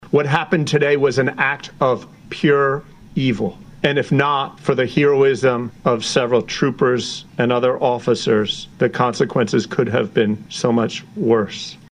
Governor Matt Meyer has been updated throughout the day on this incident and during the press conference Tuesday night said that protecting the lives of Delawareans is the most fundamental responsibility in government and that moments like today remind us of the risks that our law enforcement officers and first responders take – and the courage they demonstrate – every day….
Delaware-Governor-Matt-Meyer.mp3